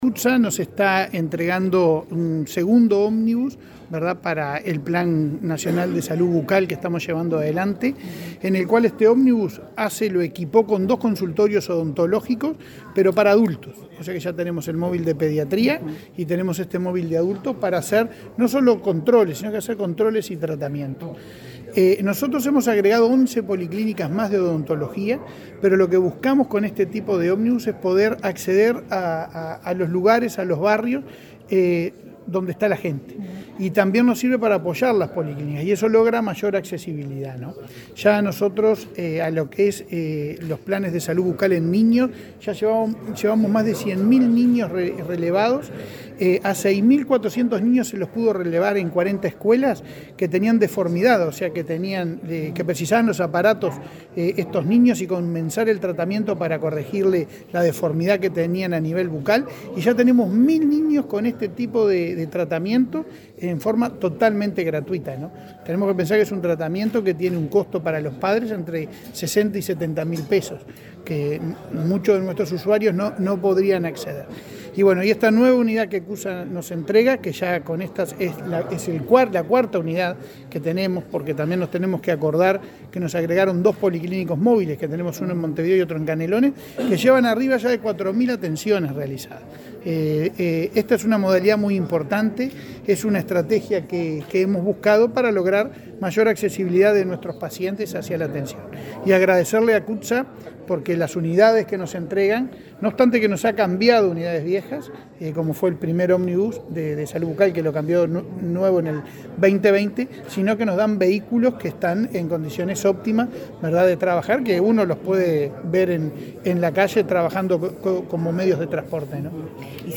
Declaraciones del presidente de ASSE, Leonardo Cipriani
Declaraciones del presidente de ASSE, Leonardo Cipriani 31/08/2022 Compartir Facebook X Copiar enlace WhatsApp LinkedIn En el marco de los dos años del Plan Nacional de Salud Bucal, ASSE firmará un comodato con la empresa CUTCSA, por el que esta donará una unidad de transporte para el referido programa. Luego, el presidente de ASSE, Leonardo Cipriani, dialogó con la prensa.